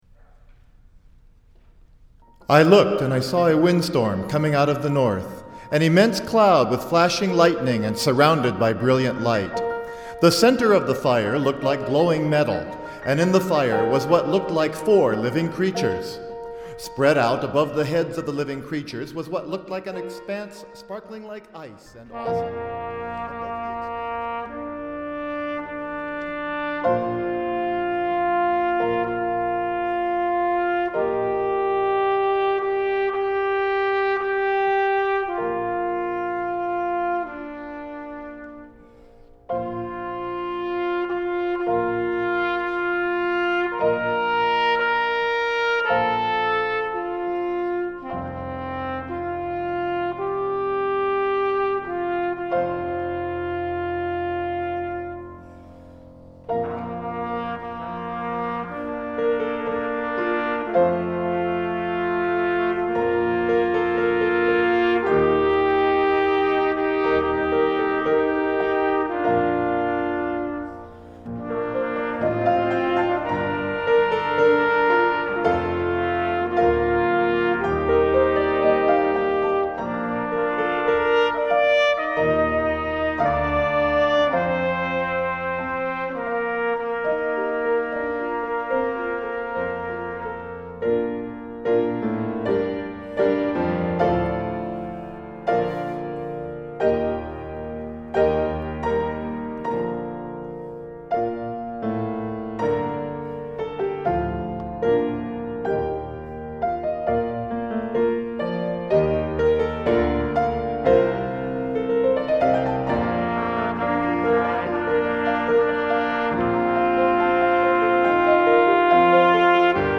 Voicing: 2 Trumpet w/P